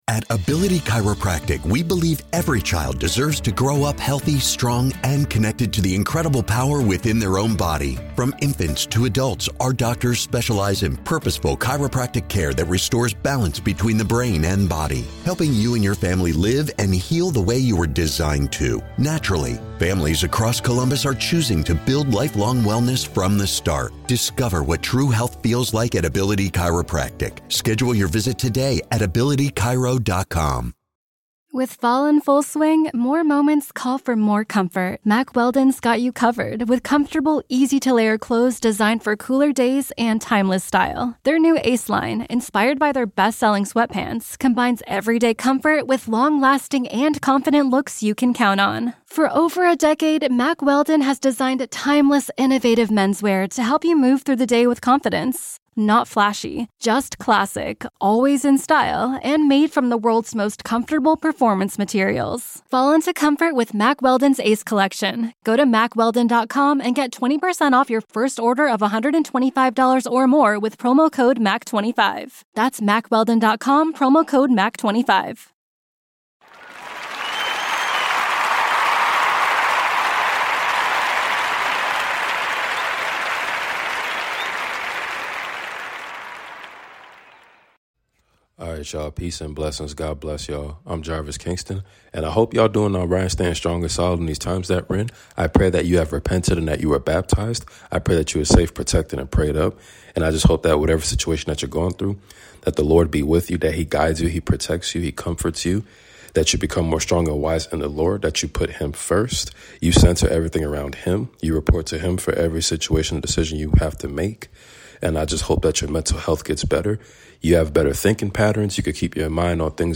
Continued reading of the book of Genesis !